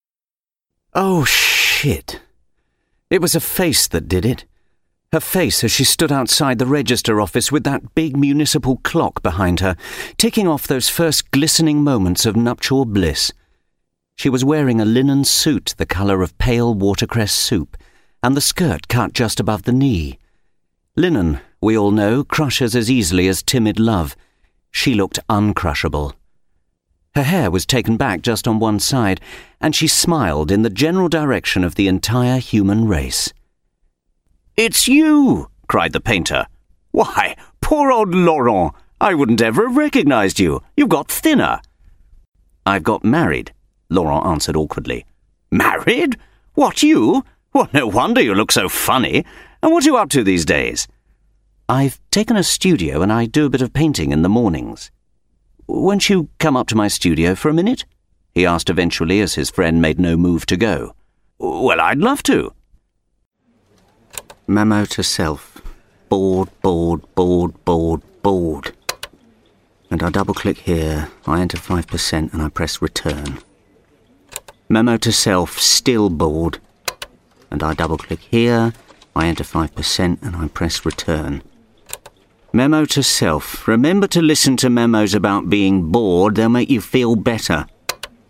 Playing age: 30 - 40s, 40 - 50s, 50 - 60s, 60+Native Accent: RPOther Accents: American, Estuary, Irish, London, Neutral, Northern, RP, Scottish, West Country, Yorkshire
• Native Accent: RP
• Home Studio